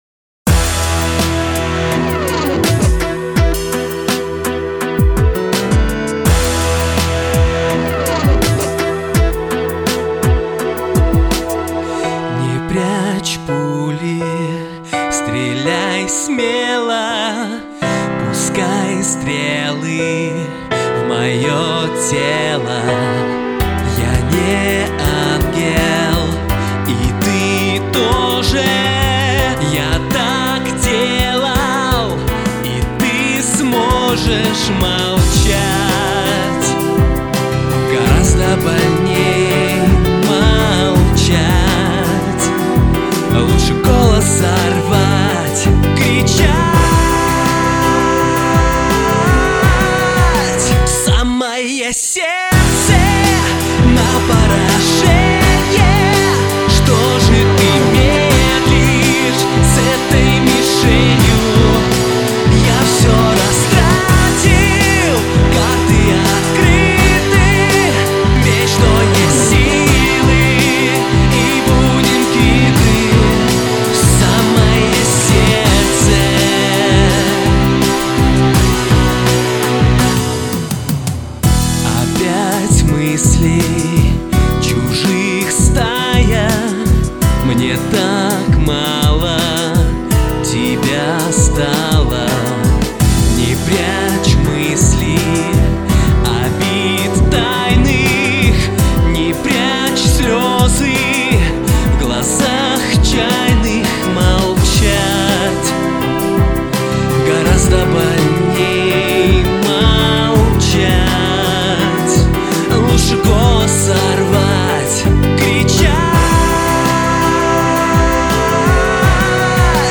Ой! Мальчики, как же красиво оба вы поёте!